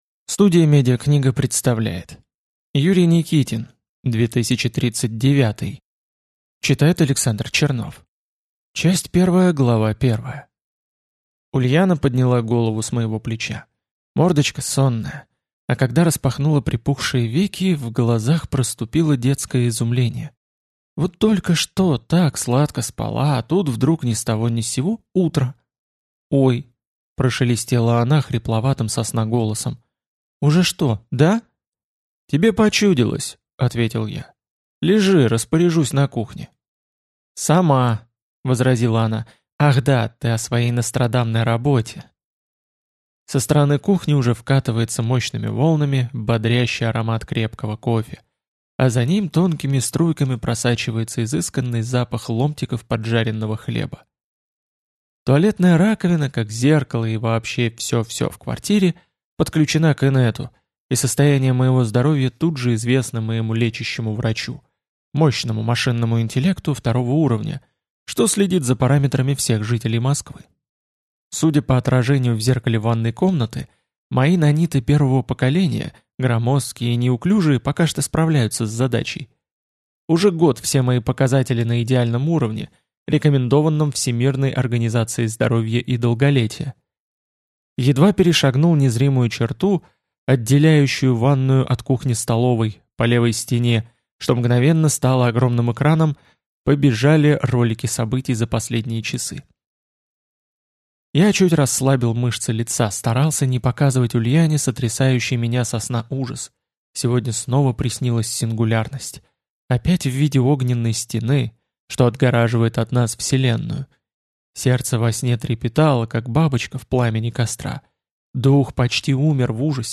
Аудиокнига 2039 | Библиотека аудиокниг
Прослушать и бесплатно скачать фрагмент аудиокниги